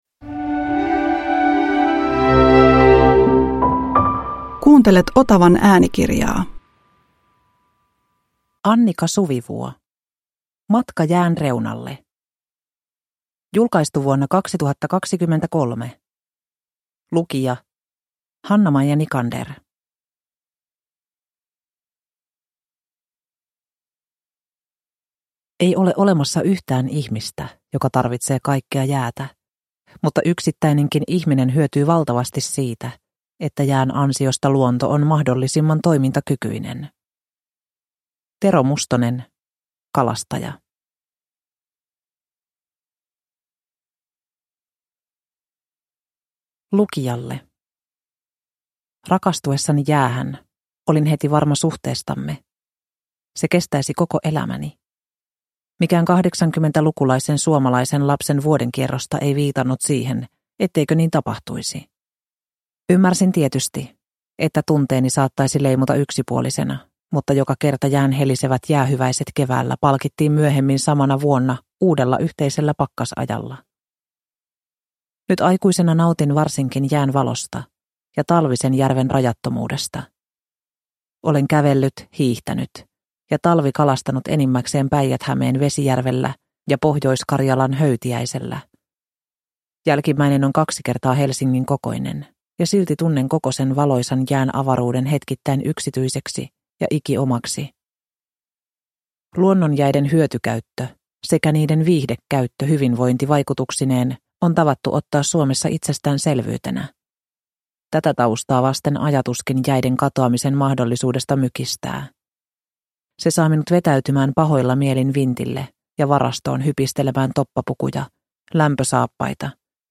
Matka jään reunalle – Ljudbok – Laddas ner